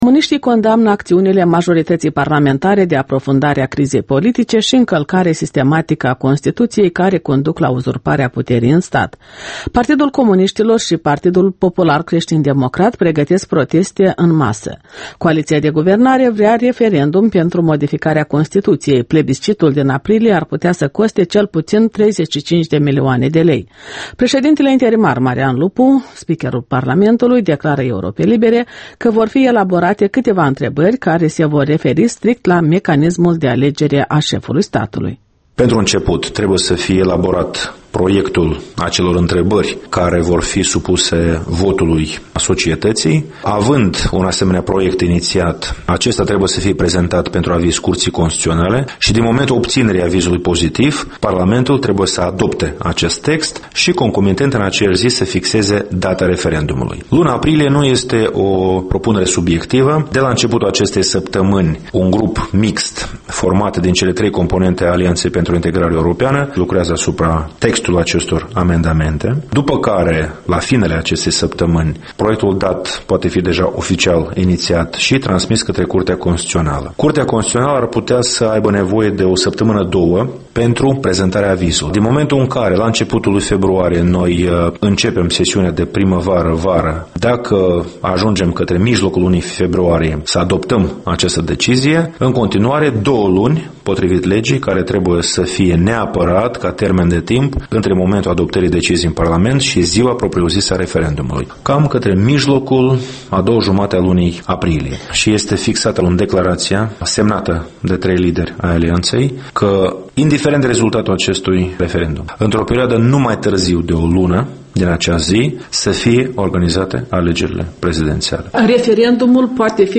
Interviul dimineții la Europa Liberă: cu președintele interimar Marian Lupu